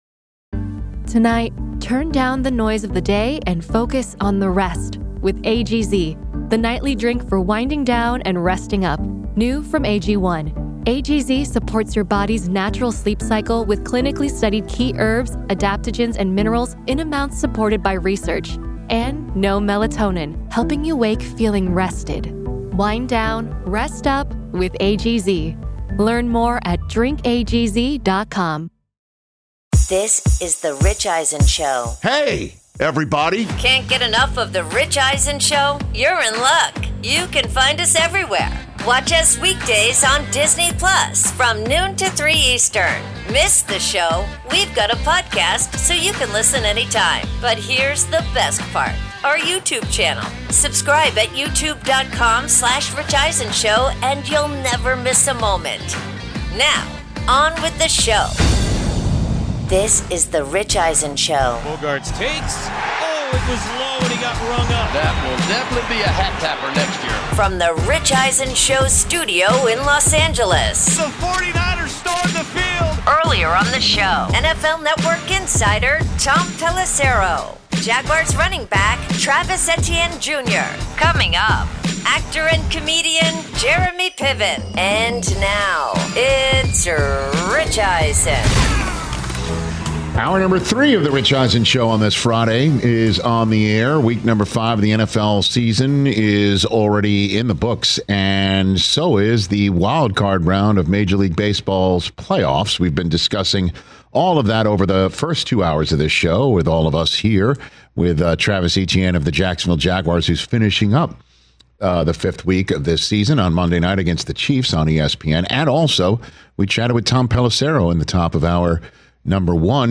Hour 3: Rams Concern Level, NFL Week 5’s Top Games, plus Jeremy Piven In-Studio